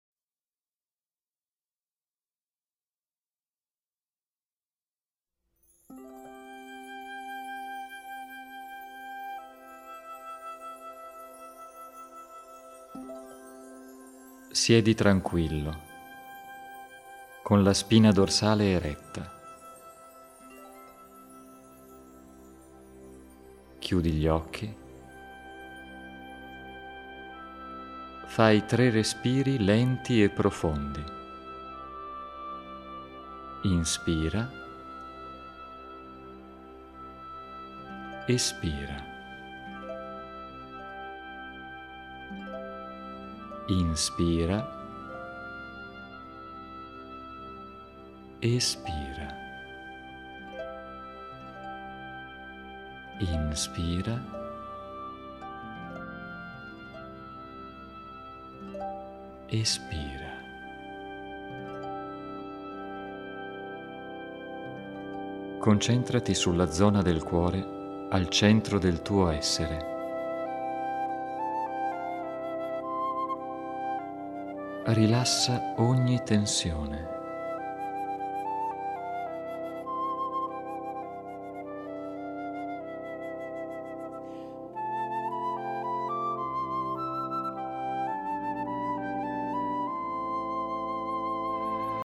10 meditazioni guidate